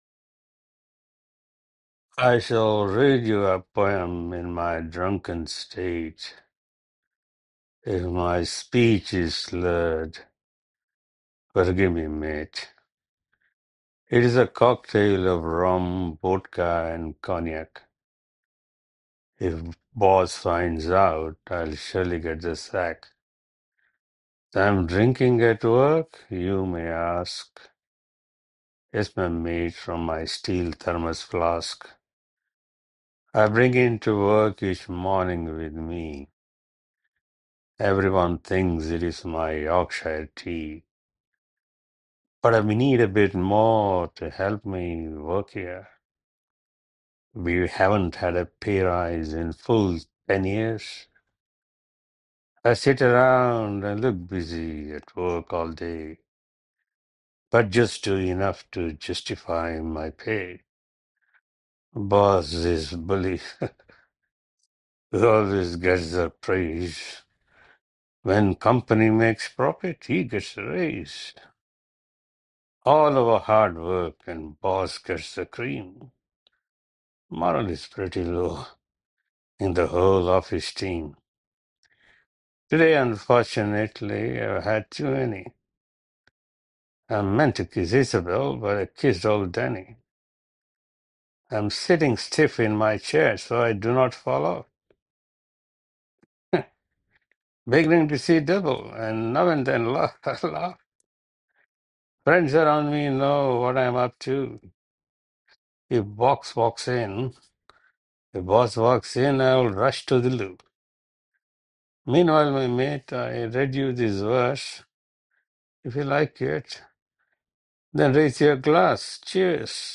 Your best Audio poem contest
Wow you have a great voice!